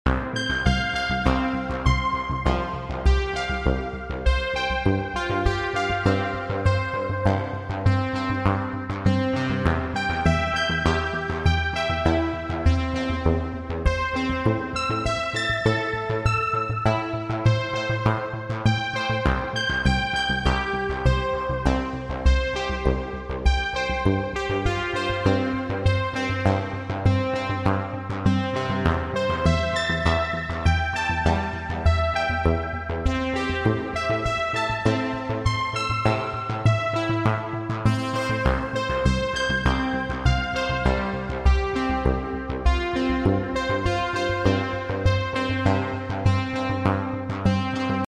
Pattern mode on the Arturia Microfreak is really fun to interact with along with the touch keyboard